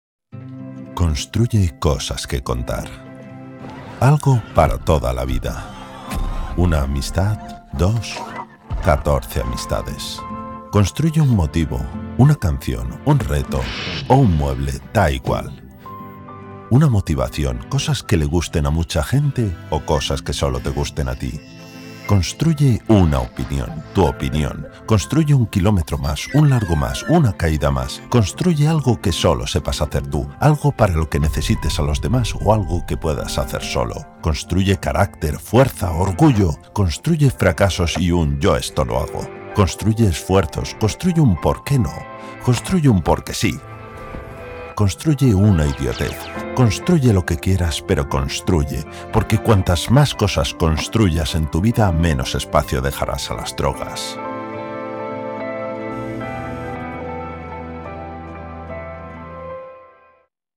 HOMBRES (de 35 a 50 años)